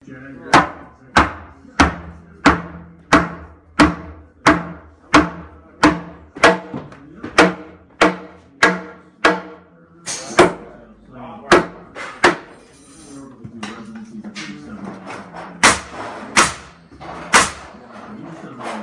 Construction Sounds Hammering a Nail
描述：Hammering a Nail
标签： Hammering hammer Construction Nail
声道立体声